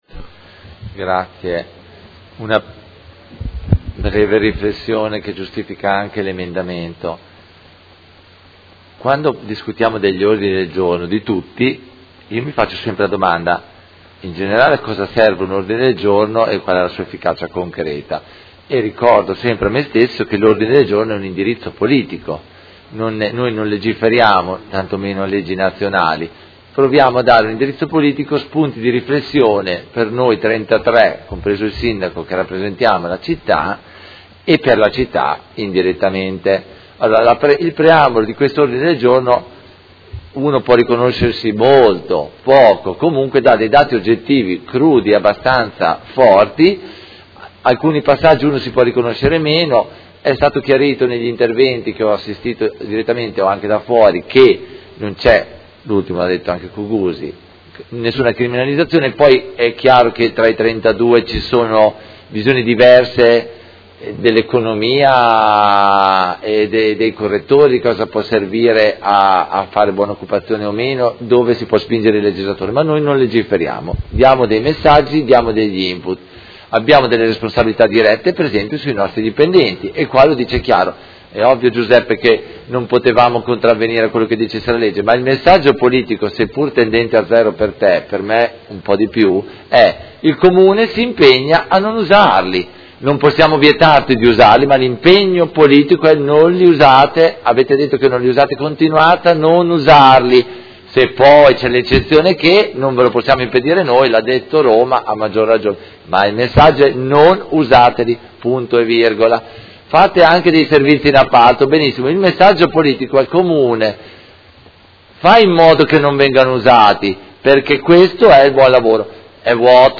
Seduta del 29/09/2016 Dibattito.